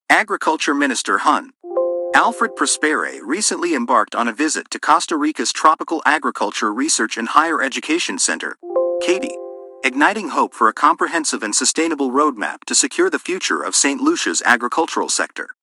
Press Release